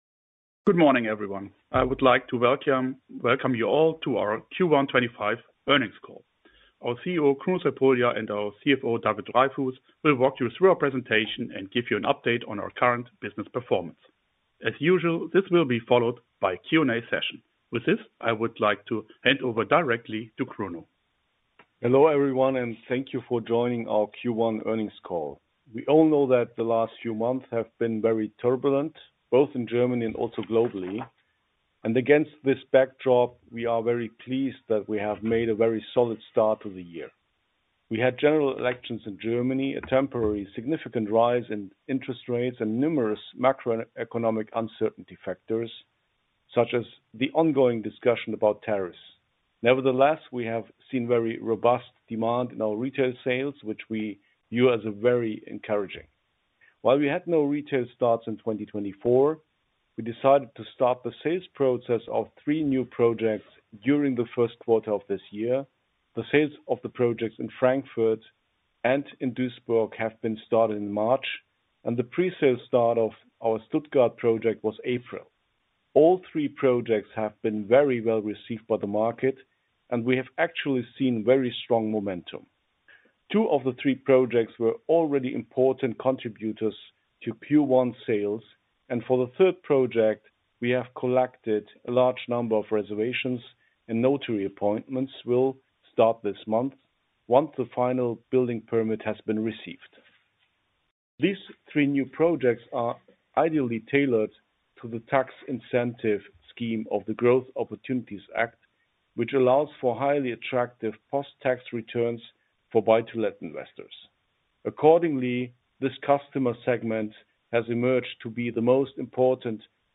Instone_Q12025_call.mp3